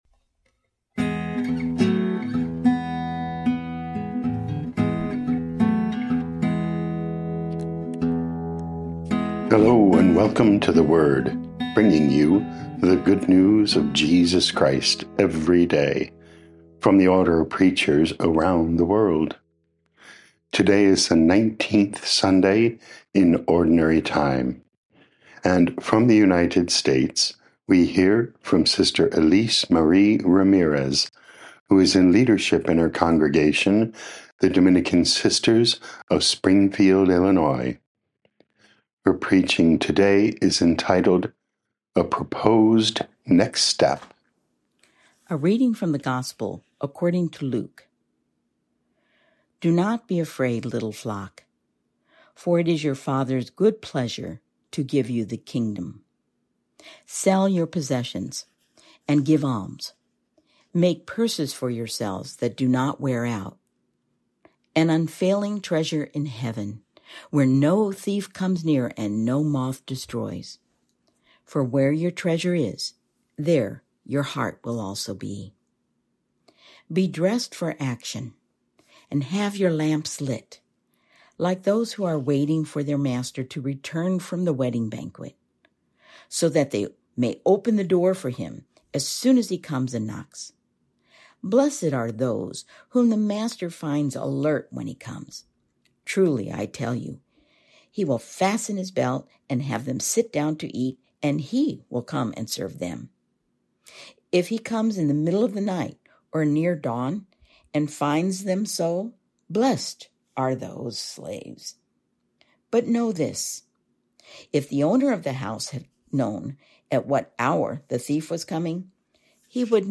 10 Aug 2025 A Proposed Next Step Podcast: Play in new window | Download For 10 August 2025, The Nineteenth Sunday of Ordinary Time, based on Luke 12:32-48, sent in from Springfield, Illinois USA.